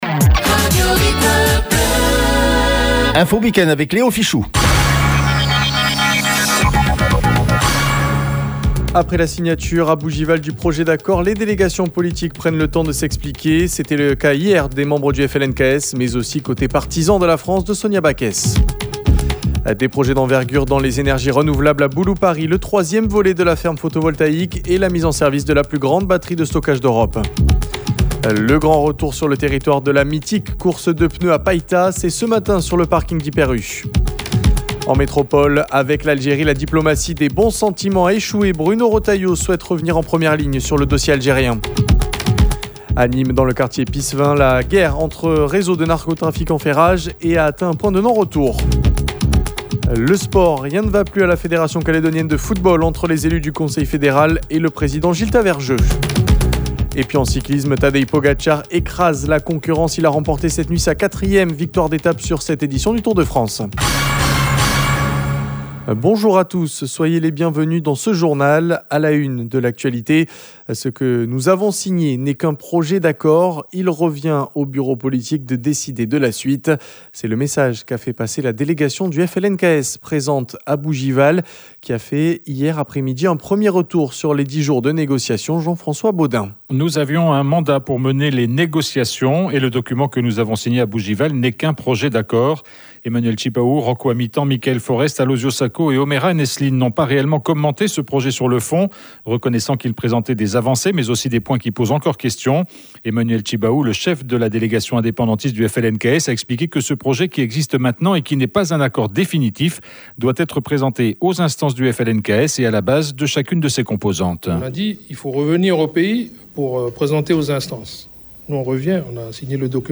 Le Journal